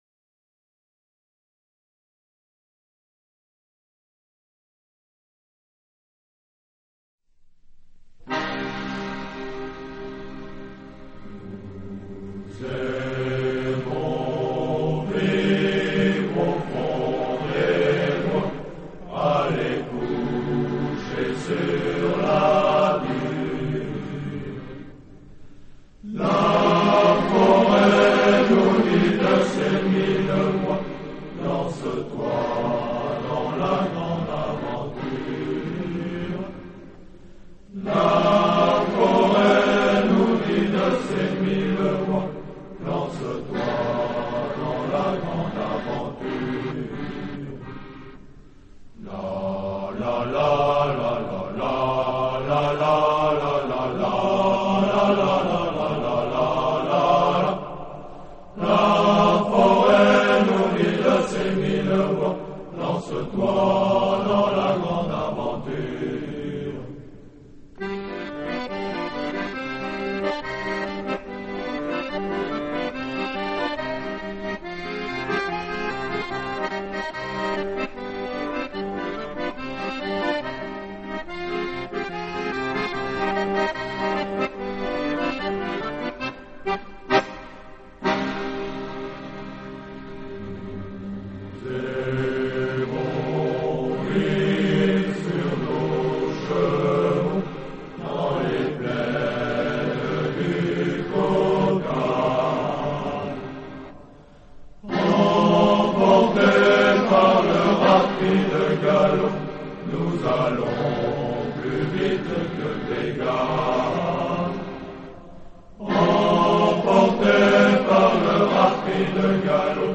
Chant-MilitaireLes-cosaques-complet3.mp3